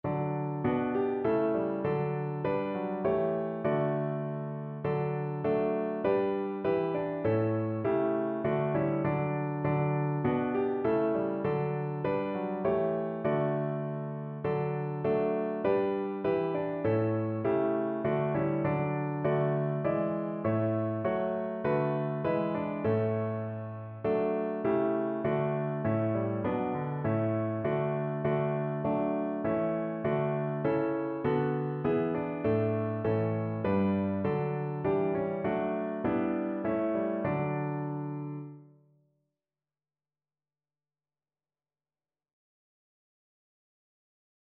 Notensatz 1 (4 Stimmen gemischt)
• gemischter Chor [MP3] 695 KB Download